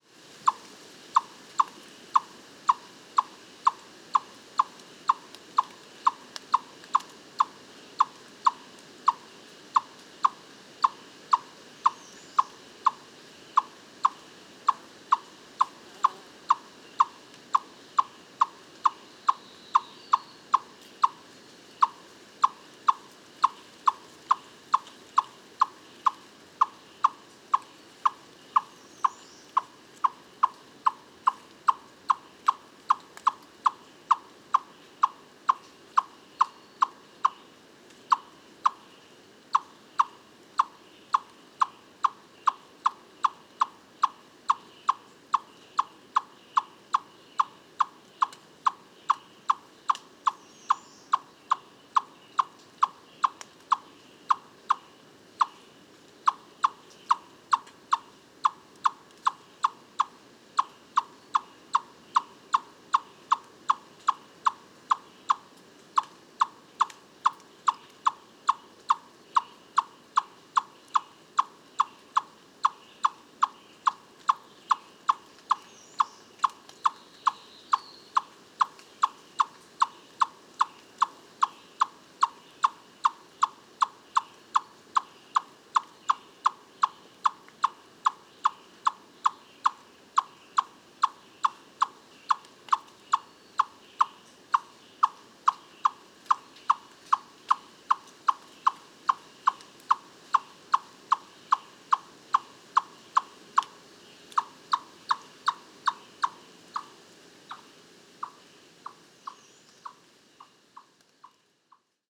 Eastern Chipmunk – Tamias striatus
‘Chuck’ Mont-Orford National Park, QC.